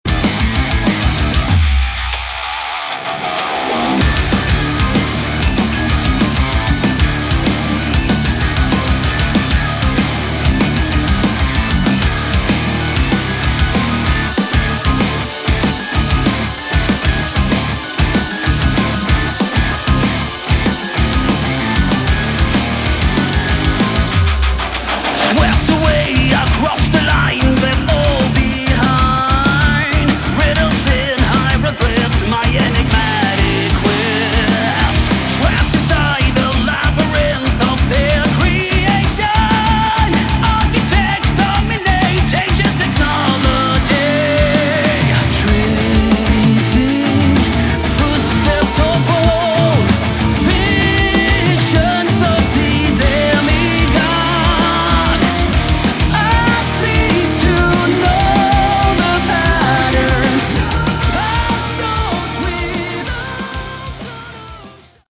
Category: Melodic Metal / Prog Metal
Guitars
Drums
Bass
Keyboards
Vocals